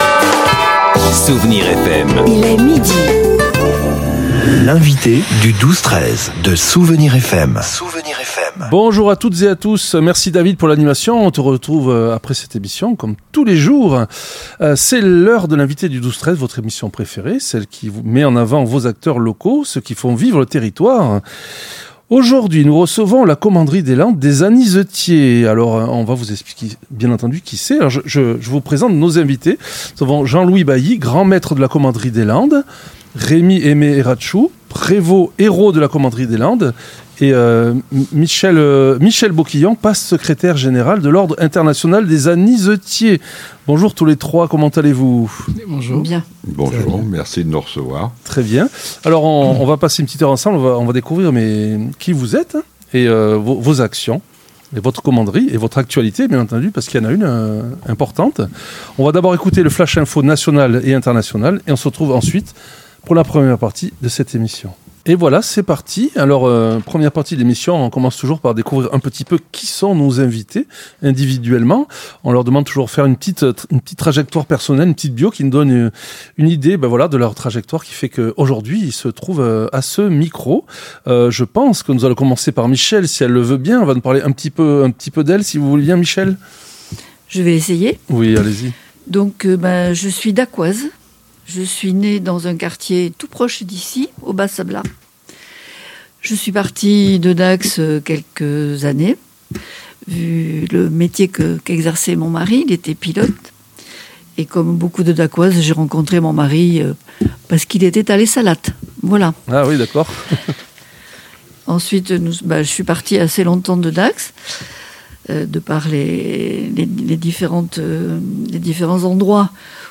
L'entretien a permis de découvrir la Commanderie des Landes, une structure dynamique et moderne où la mixité est à l'honneur, et où le cérémonial d'intronisat